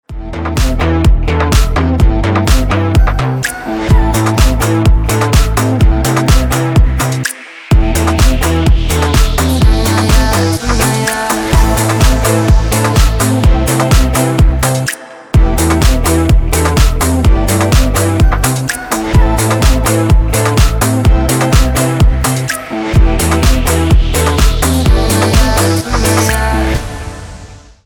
• Качество: 320, Stereo
гитара
женский голос
Mashup
Стиль: club house